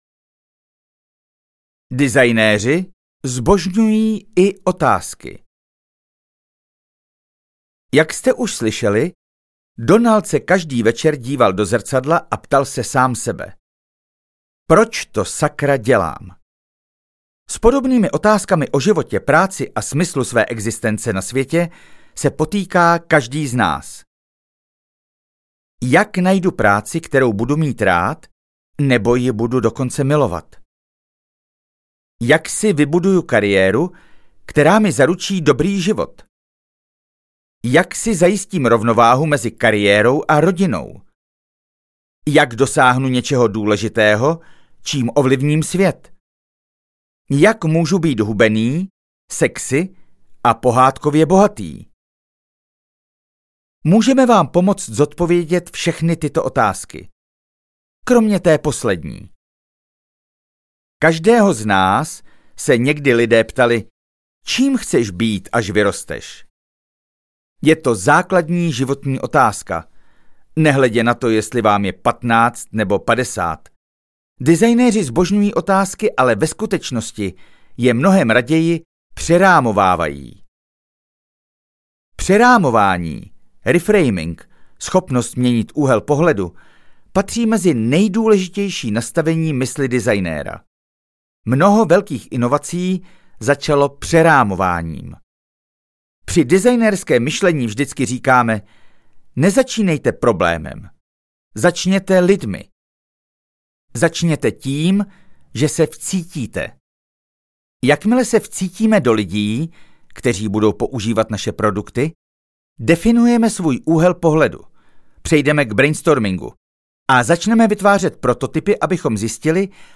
Designérem vlastního života audiokniha
Ukázka z knihy